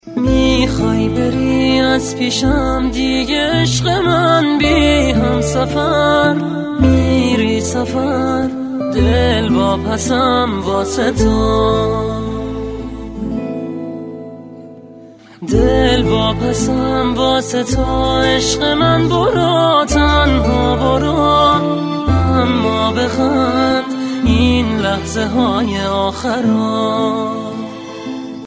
رینگتون احساسی و رمانتیک(با کلام)